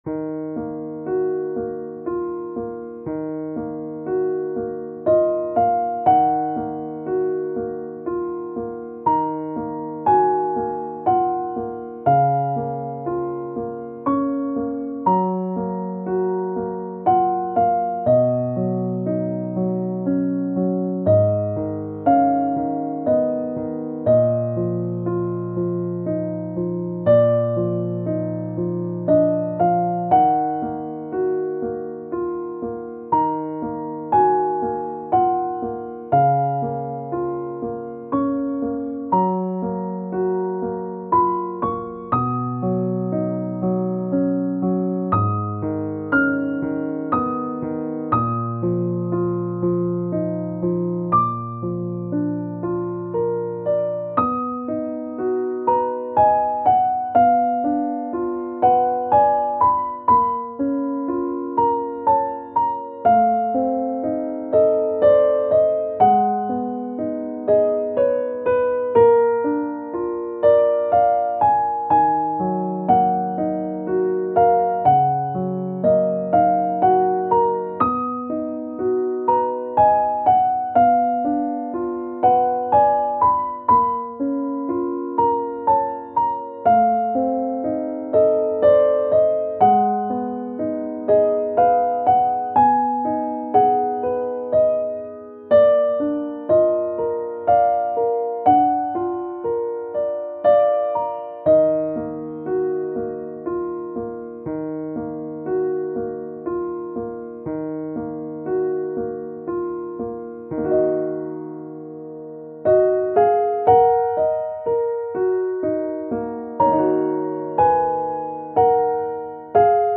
ogg(L) - 悲愴 しっとり スロウ